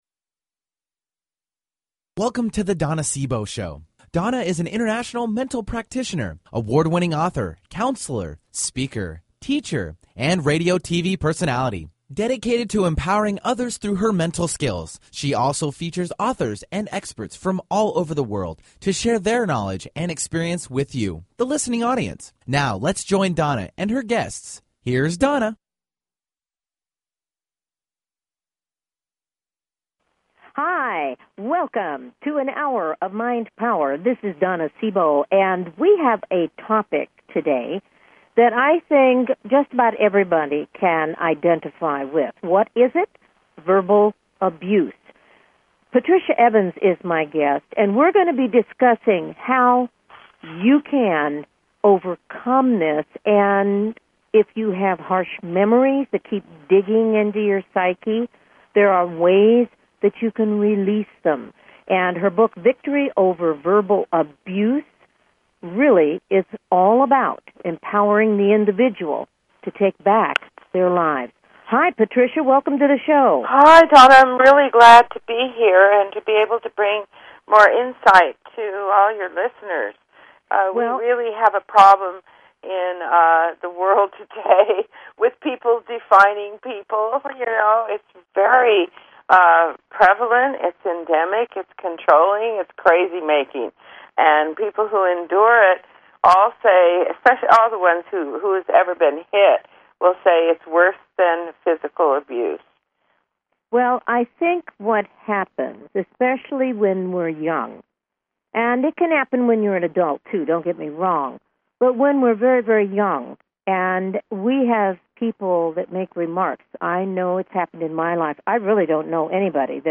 Talk Show Episode
Callers are welcome to call in for a live on air psychic reading during the second half hour of each show.